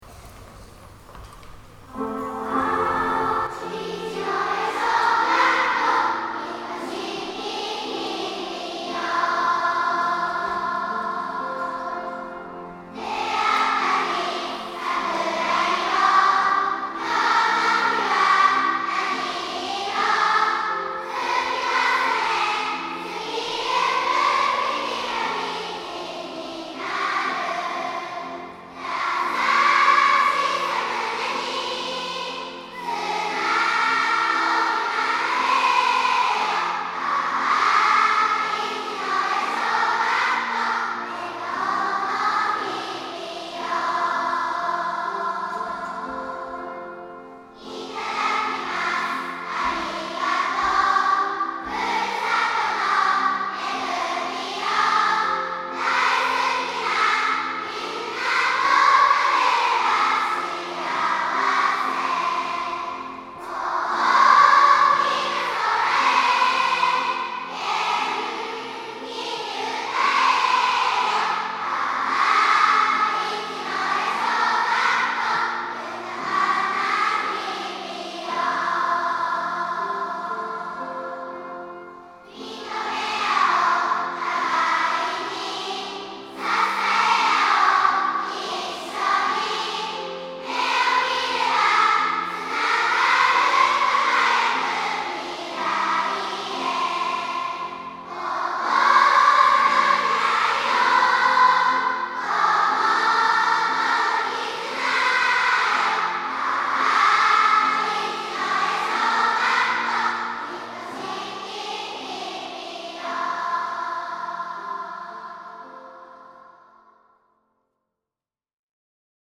校歌 - 流山市立市野谷小学校
↓はじめての全校合唱が流れます↓ 開校を祝う会.mp3 ４月２８日（水）に開校を祝う会を行ったときの全校合唱です。